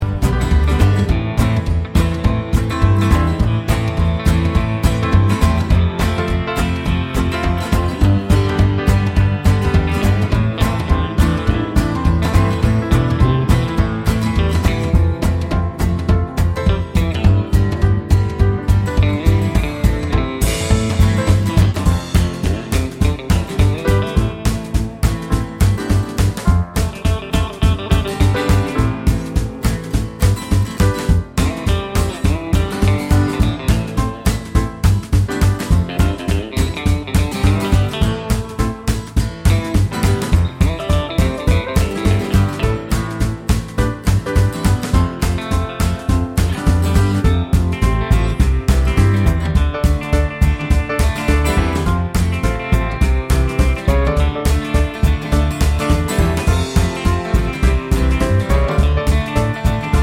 Minus Saxophone Jazz / Swing 4:21 Buy £1.50